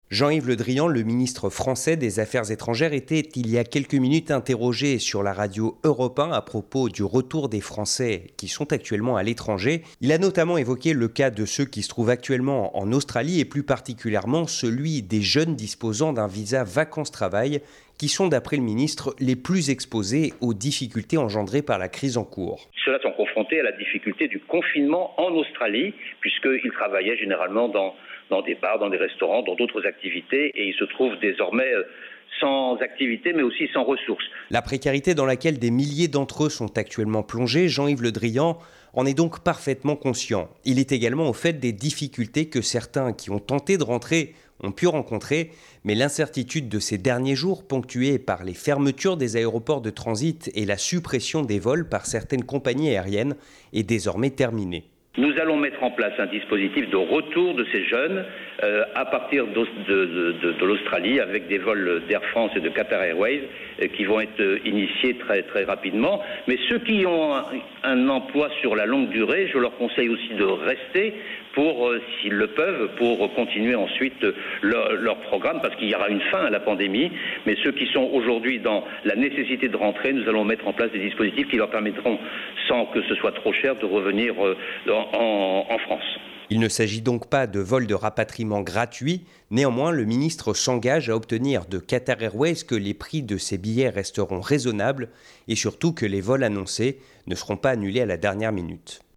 Le ministre français des Affaires étrangères s'est exprimé ce matin sur Europe 1 à propos du retour des Français à l'étranger et évoqué spécifiquement le cas de l'Australie.